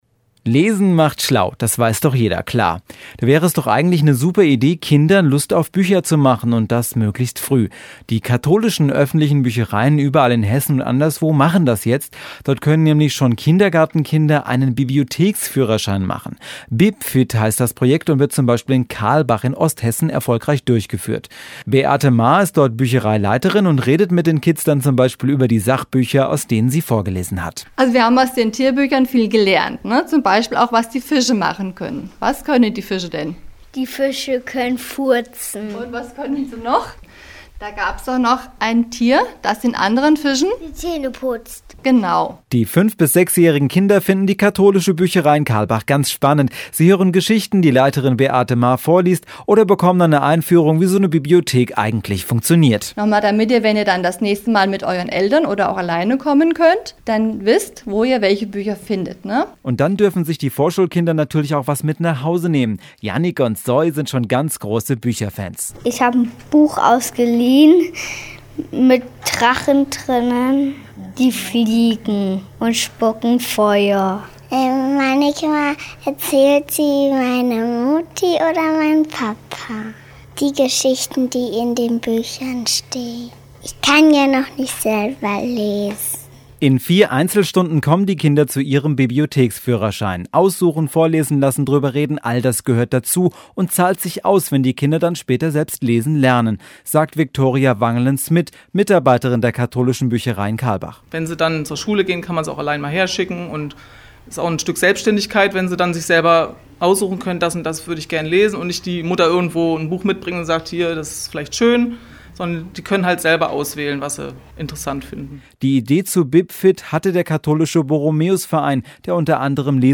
Interview zur Bibfit Aktion (in Kalbach) bei FFH